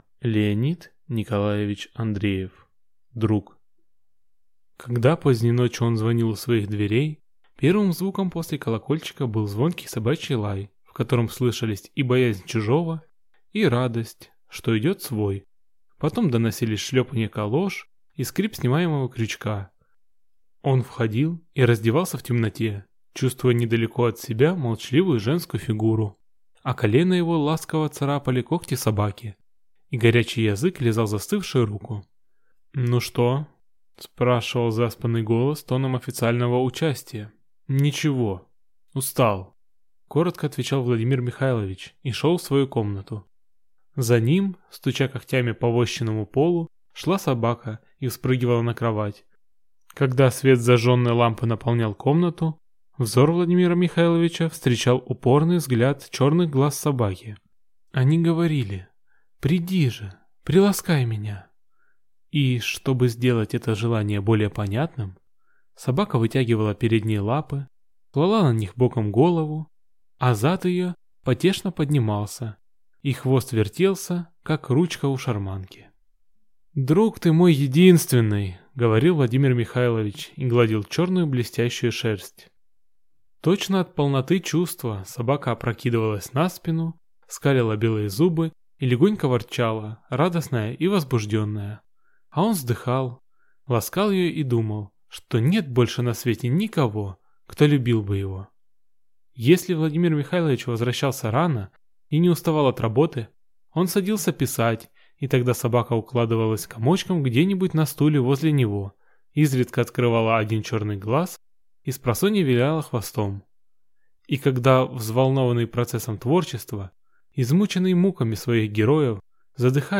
Аудиокнига Друг | Библиотека аудиокниг